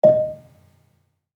Gambang-D#4-f.wav